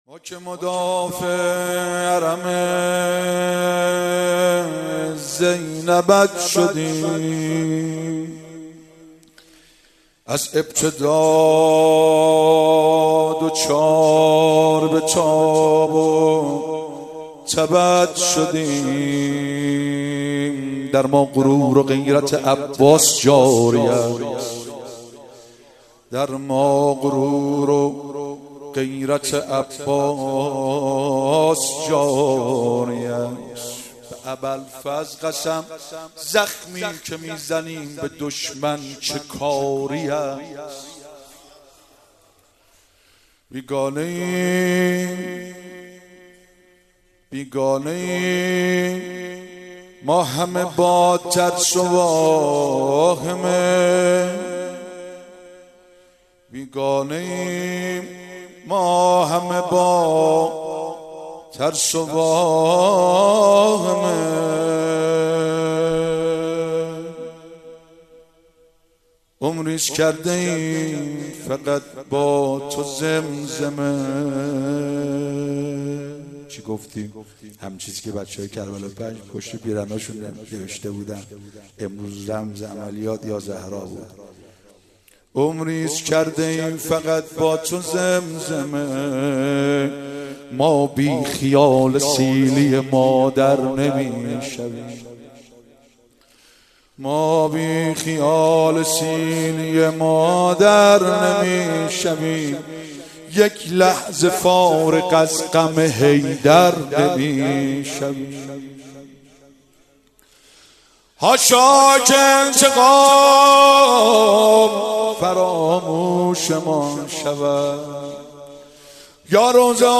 20 دی 96 - هیئت فاطمیون - رجز خوانی